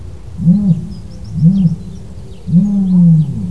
autruche.wav